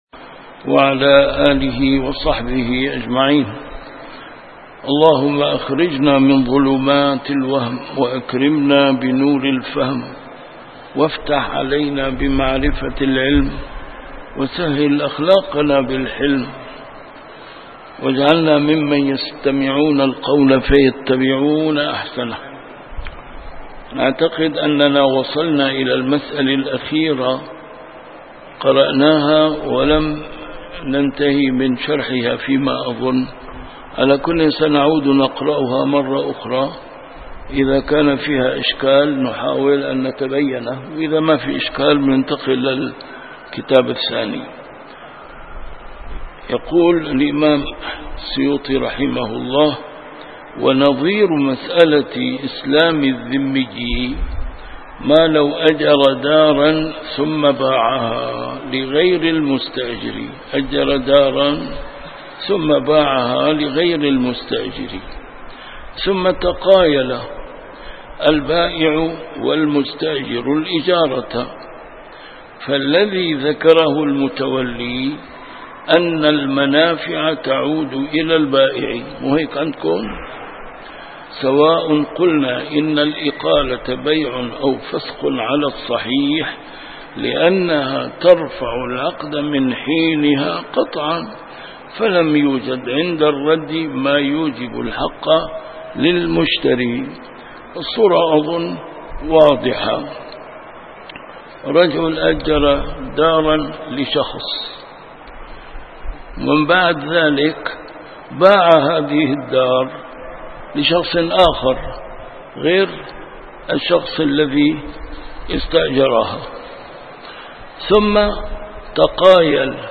A MARTYR SCHOLAR: IMAM MUHAMMAD SAEED RAMADAN AL-BOUTI - الدروس العلمية - كتاب الأشباه والنظائر للإمام السيوطي - كتاب الأشباه والنظائر، الدرس الثامن والستون: كل ما ورد به الشرع مطلقاً بلا ضابط منه ولا من اللغة يرجع فيه إلى العرف